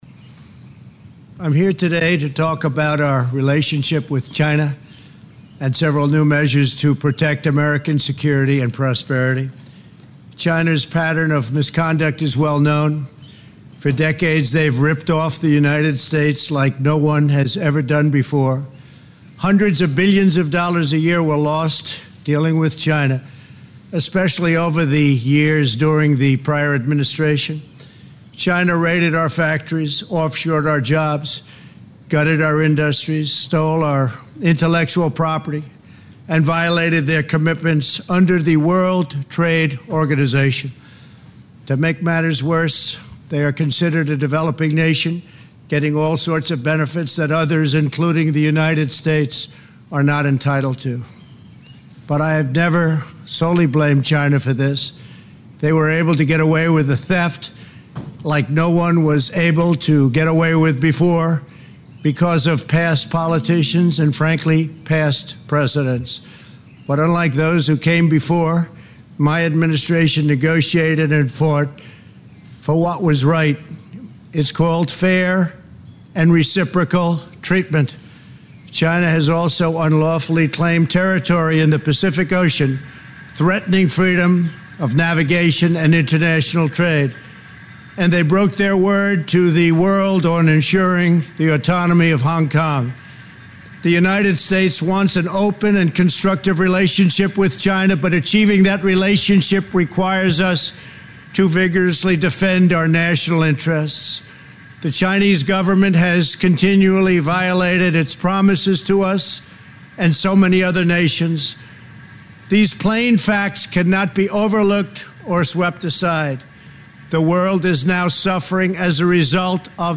Press Statement on U.S.-China Relations and Departure from the World Health Organization
delivered 29 May 2020, White House Rose Garden, Washington, D.C.